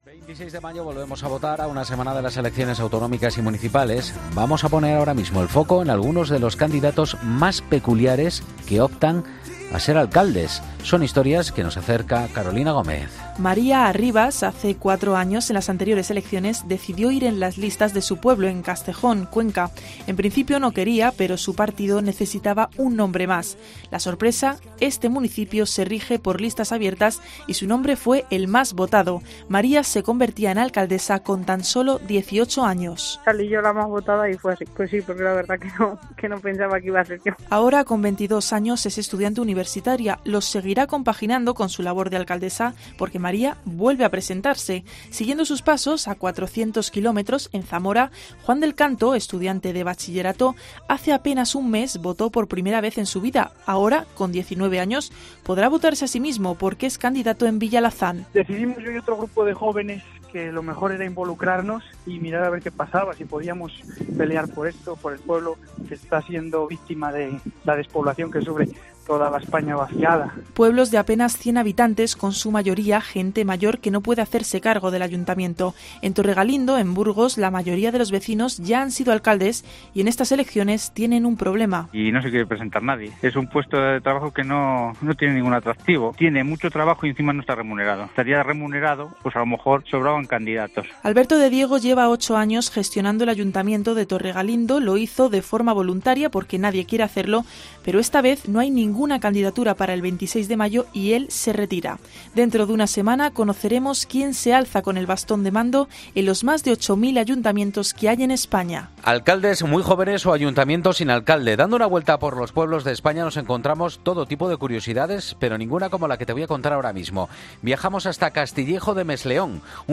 “Como mejoré el PP me volvió a meter en el follón” asegura entre risas.